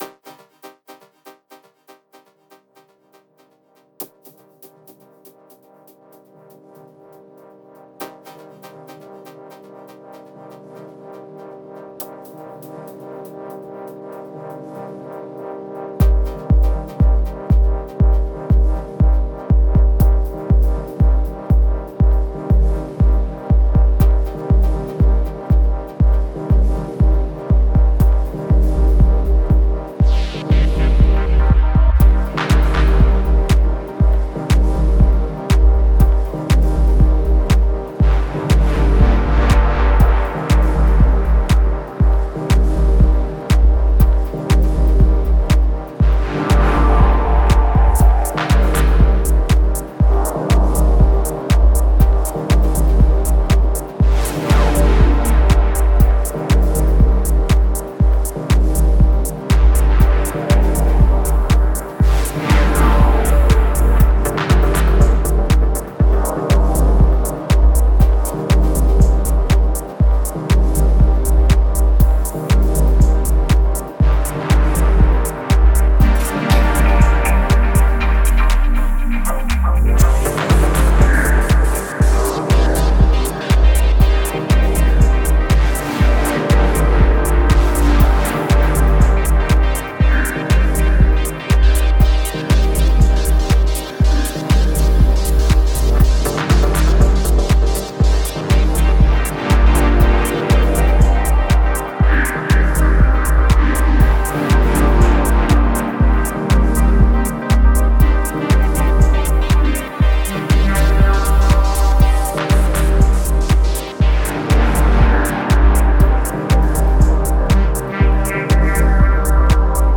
Genre: Dub Techno.